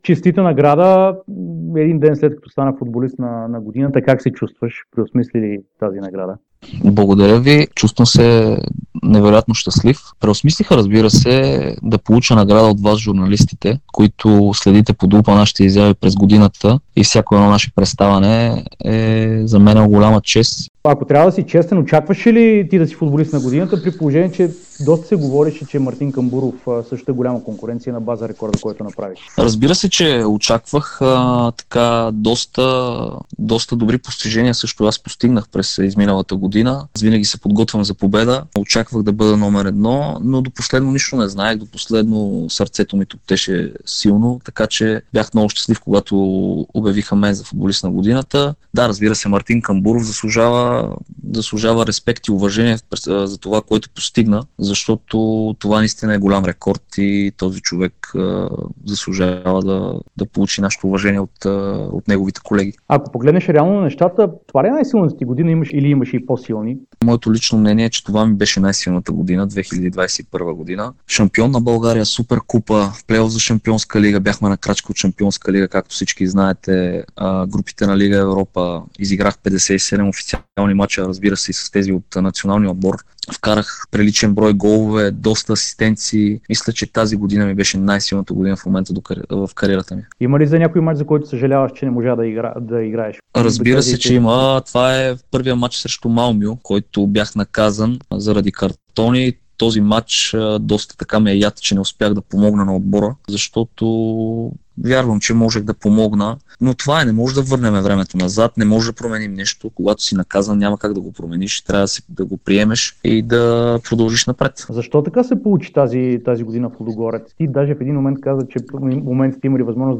Бях много щастлив, когато ме обявиха мен за футболист на годината“, сподели Десподов в интервю за Дарик радио и dsport.